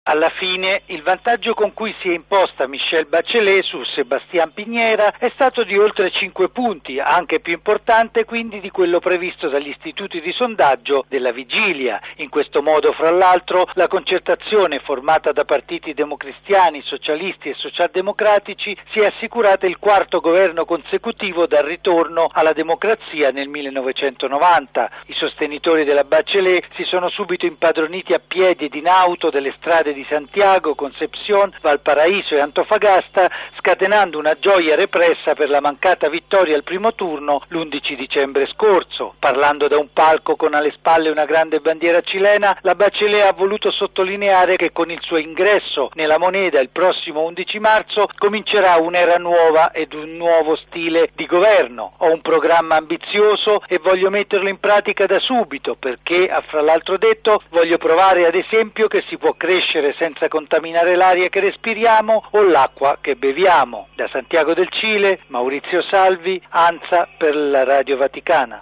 La Bachelet, che occuperà la poltrona che fu di Allende e dello stesso Pinochet, è la prima donna democraticamente eletta capo di Stato in Sudamerica. Da Santiago del Cile